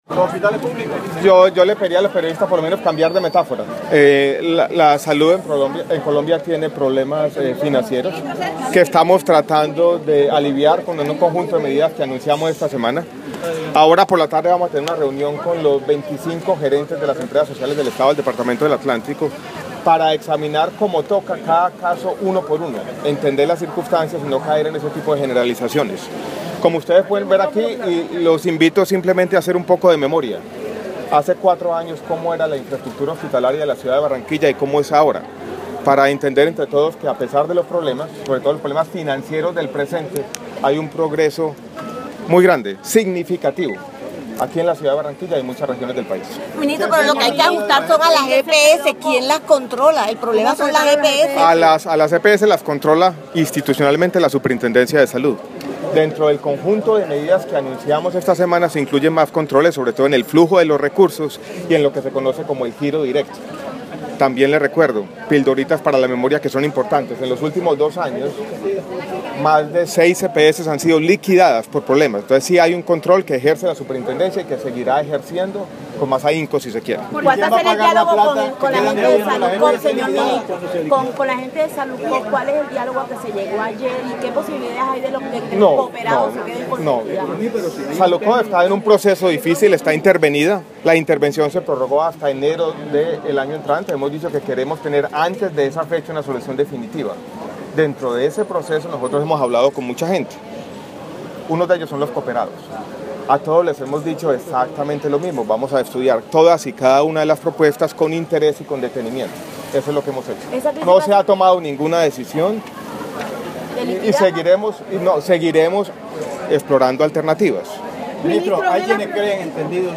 Audio: declaraciones del ministro Alejandro Gaviria en Barranquilla